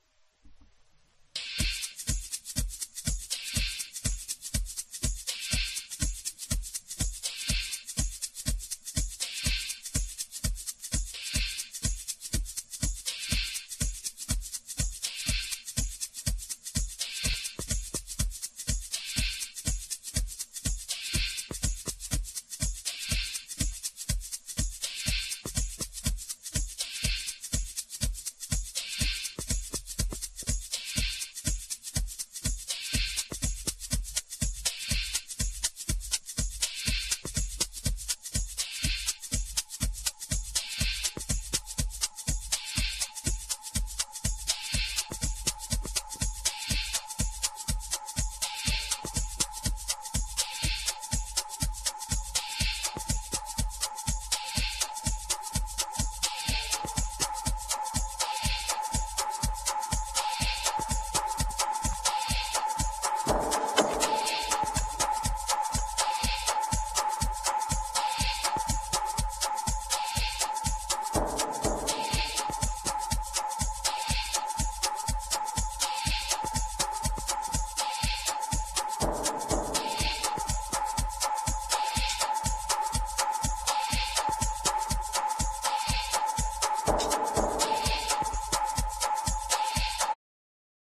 ELECTRO HOUSE / TECH HOUSE